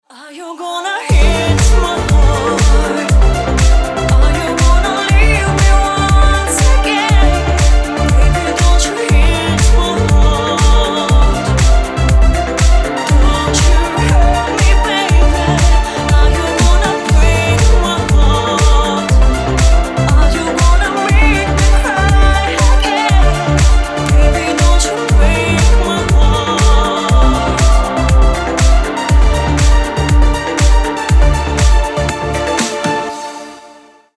• Качество: 320, Stereo
красивый женский голос
Стиль: deep house